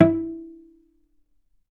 vc_pz-E4-ff.AIF